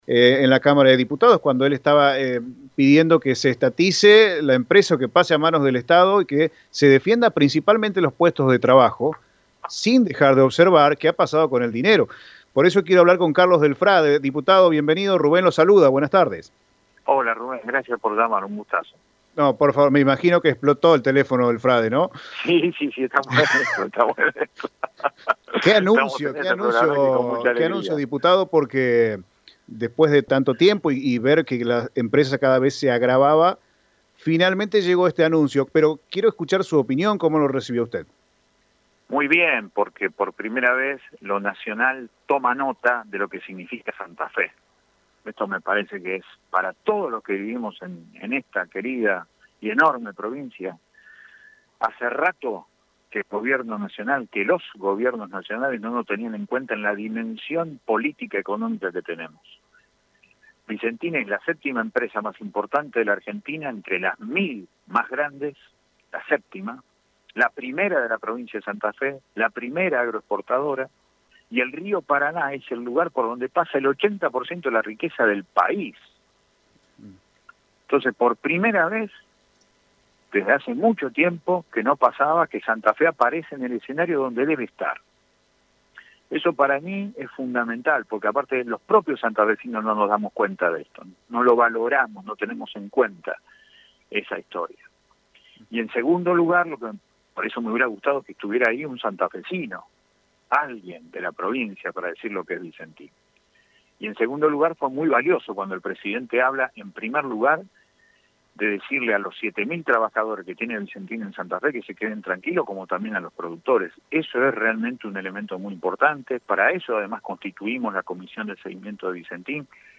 Desde la Legislatura santafesina, el diputado Carlos Del Frade, uno de los principales promotores de la estatización de la empresa, dio su punto de vista en Radio EME.